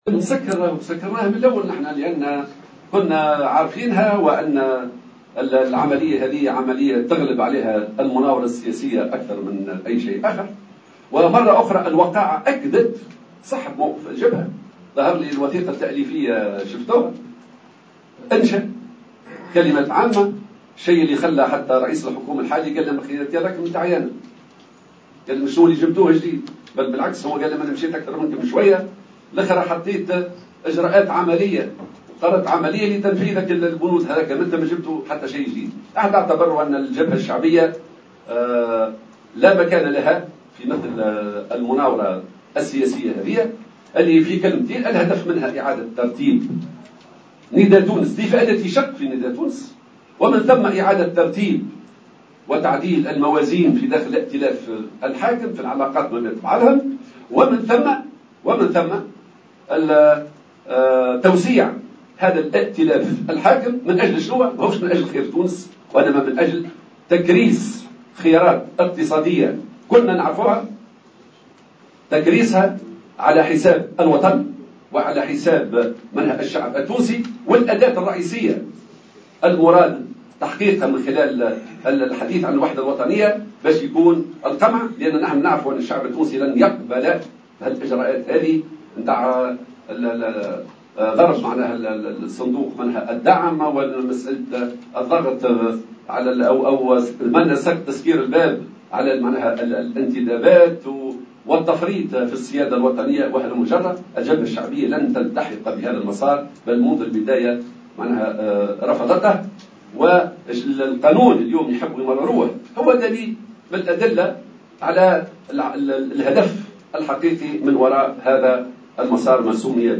وقال في ندوة صحفية عقدها في تونس العاصمة اليوم إن الجبهة رفضت منذ البداية مبادرة رئيس الجمهورية لتشكيل حكومة وحدة وطنية ومحتوى الوثيقة الذي ستنطلق منه هذه الحكومة.